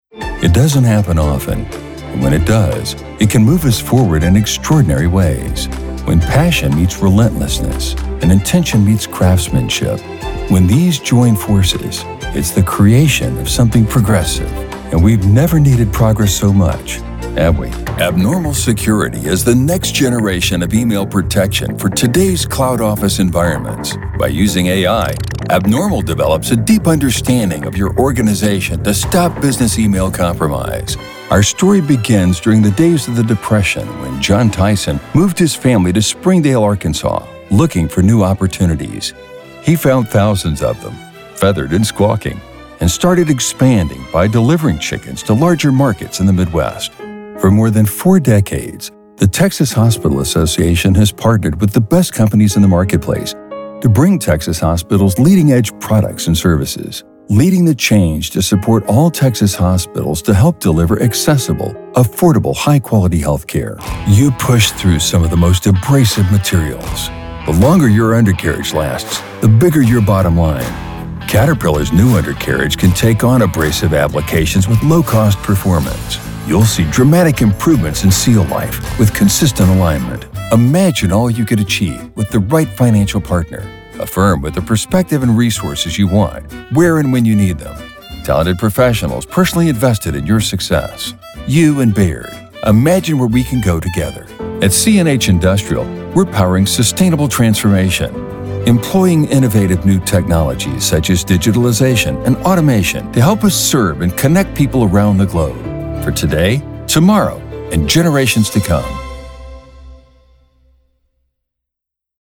Powerful, deep male Voiceover with over 27 years experience in all kinds of projects
Corporate Narration
Texan Southwestern
Corporate Narration.mp3